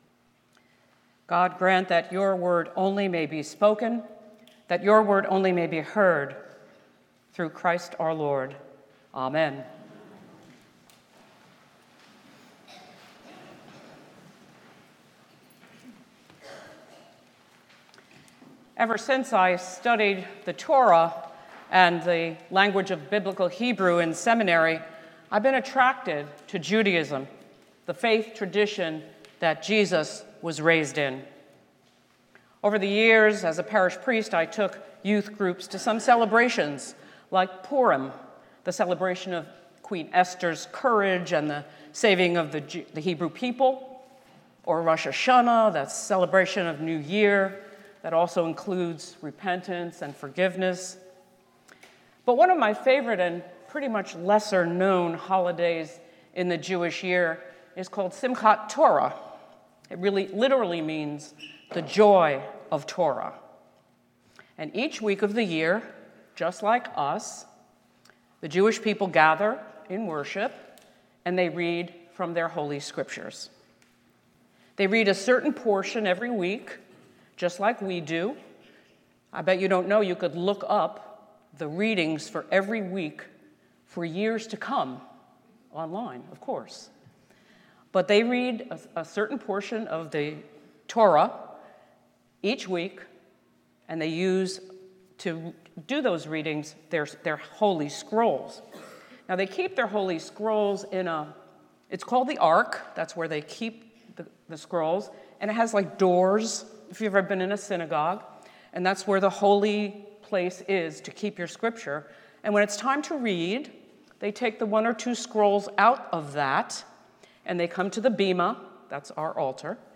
St-Pauls-HEII-9a-Homily-23NOV25.mp3